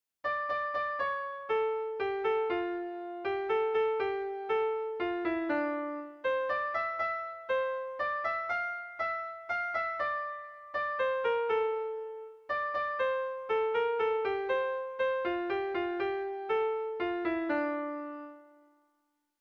Bertso melodies - View details   To know more about this section
ABDE